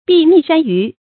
避溺山隅 注音： ㄅㄧˋ ㄋㄧˋ ㄕㄢ ㄧㄩˊ 讀音讀法： 意思解釋： 指防止淹死而躲入山隅。